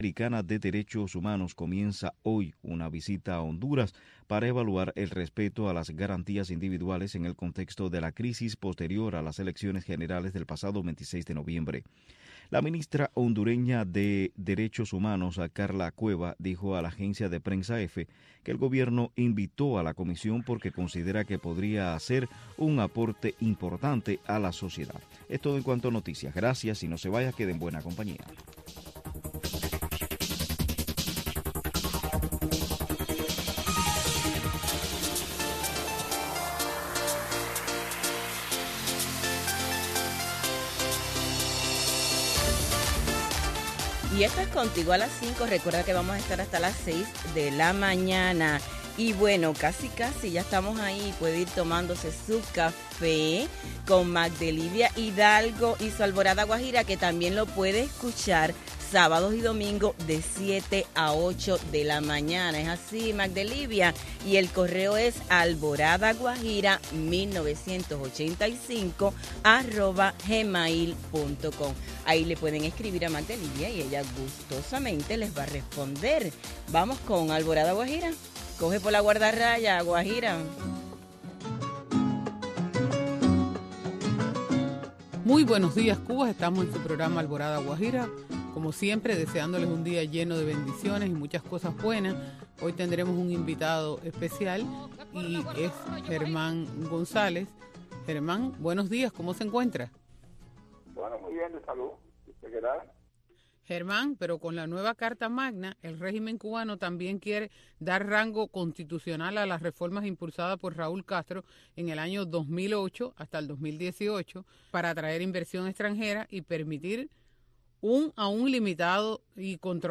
Una revista cultural y noticiosa